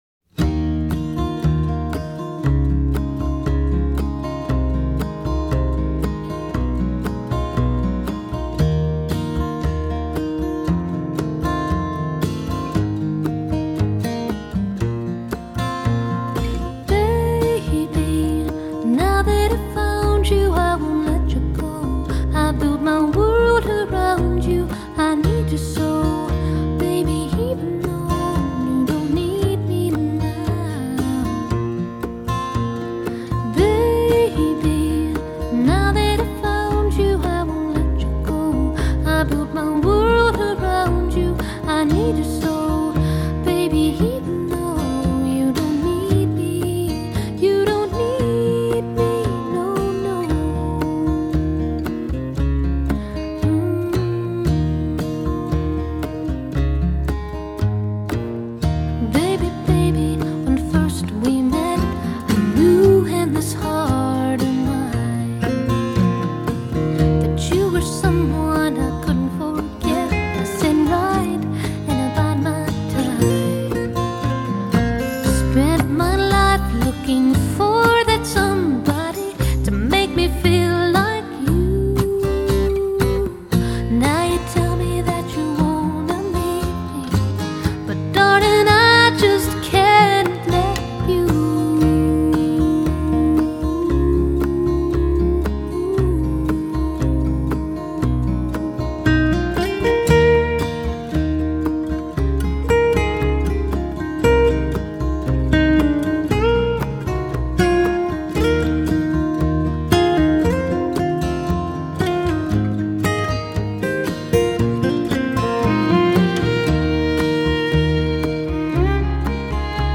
★ 藍草音樂天后早期代表作，雙白金暢銷專輯！
★ 全音音樂網站五顆星無條件推薦，《滾石》雜誌四星高評，歌曲首首動聽，錄音鮮活絲滑！